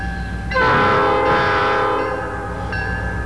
Horn bells cast from both the new and old patterns have found their way onto new P5's in various combinations, resulting in a wide variety of different, often dischordant, sounds.
Cuyahoga Valley Scenic Ry. (ex-NS):